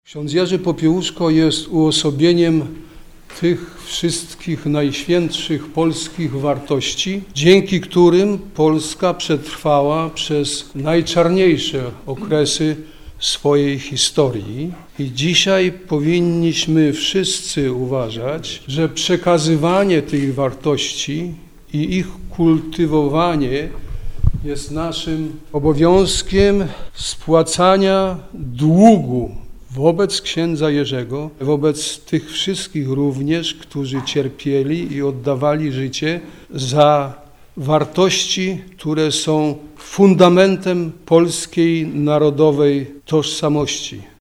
O niezłomności kapelana mówił radny Sejmiku Województwa Dolnośląskiego, Tytus Czartoryski.